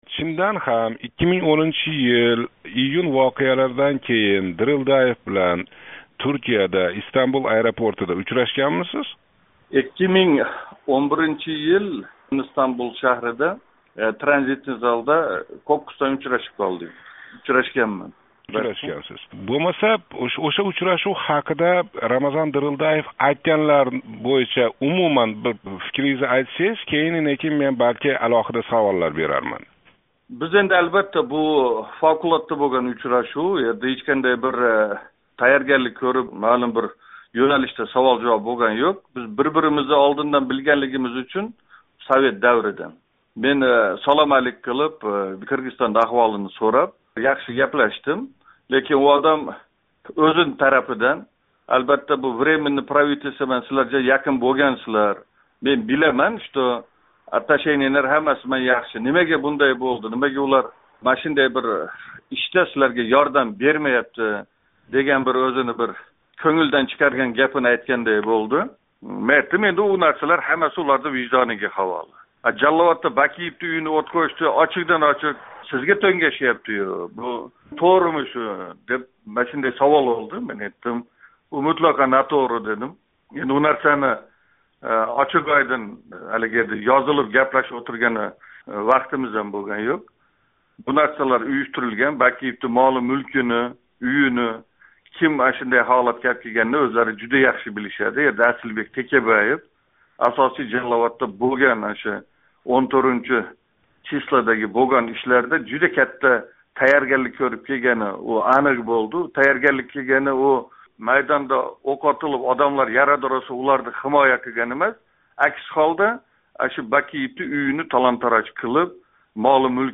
Қодиржон Ботиров билан суҳбат